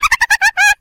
Звук смеха выдры